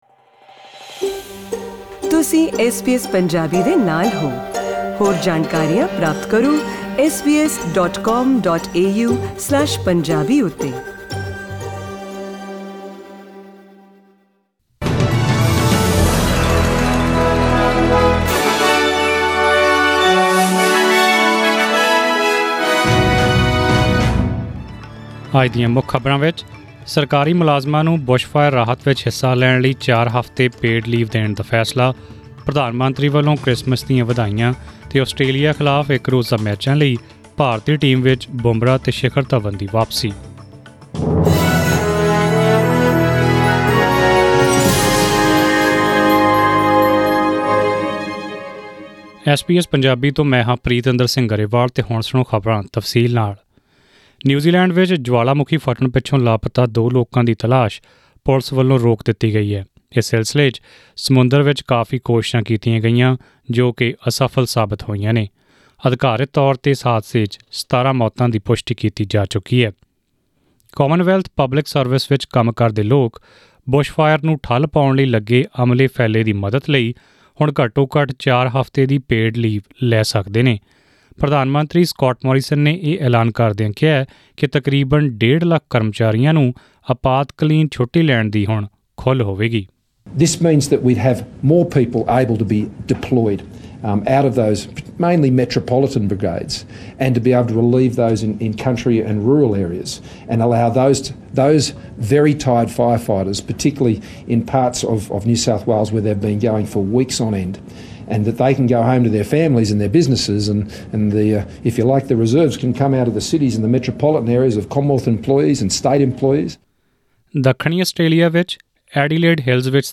In today’s news bulletin - New Zealand authorities end the search for the two missing people following the White Island eruption.